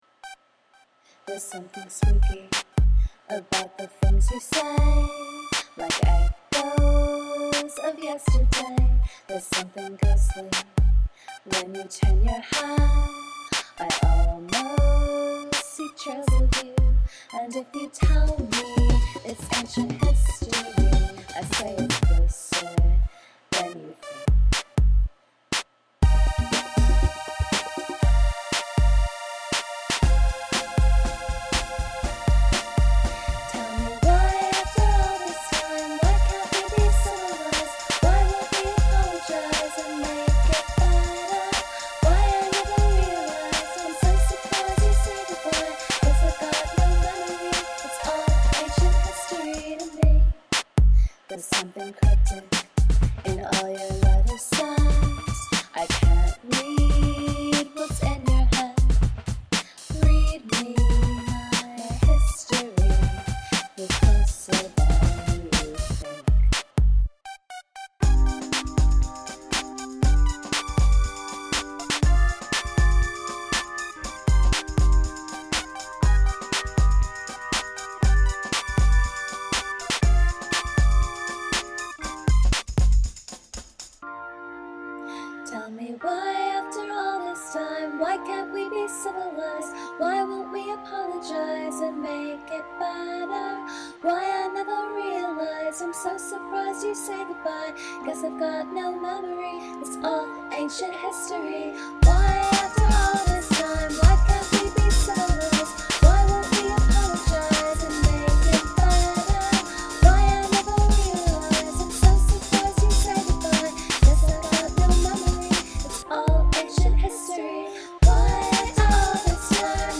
Cm G Dm7 Cm
Eb Bb Fm Bb
A A B C A B A C C C C
I dig the little melody synth and especially that organ sound. The various loops are pretty cool.
Your backing vocals are, as usual, killer, especially all the spread out stuff over that last chorus section.